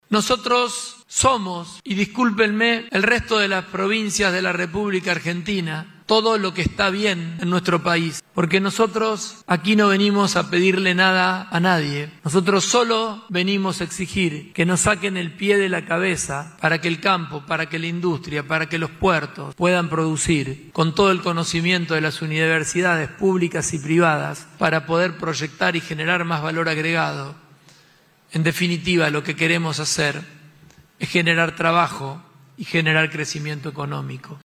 Con el lema “Un modelo de desarrollo desde el interior productivo”, se llevó a cabo en la ciudad de Santa Fe la XVIII Reunión Institucional de la Región Centro.
PULLARO-REGION-CENTRO-SOMOS-TODO-LO-QUE-ESTÁ-BIEN.mp3